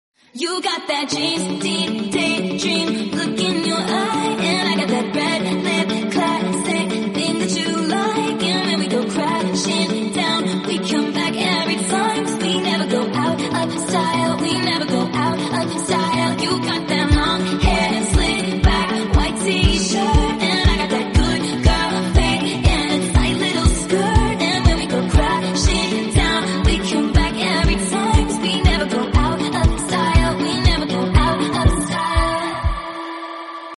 Dubai deira Abra sound effects free download